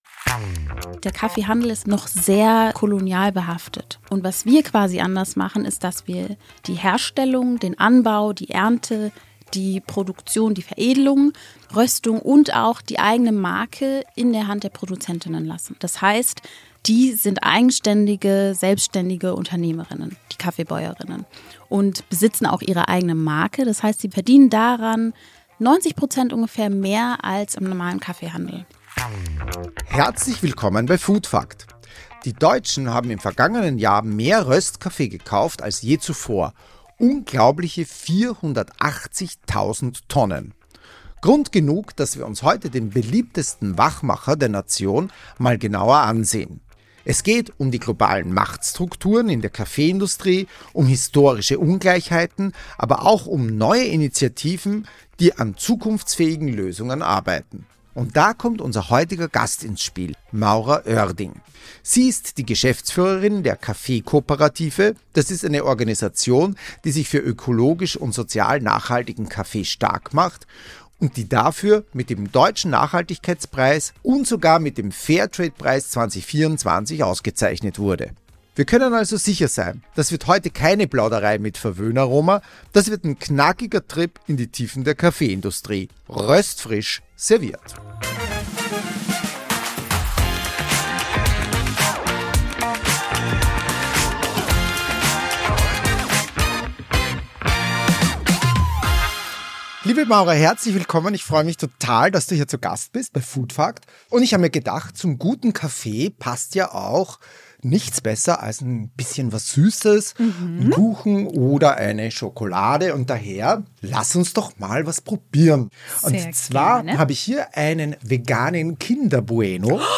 Freut euch auf ein unterhaltsames und lehrreiches Gespräch über guten Kaffee, starke Frauen wie ihr mit eurem Kaffeekonsum einen positiven Einfluss ausüben könnt!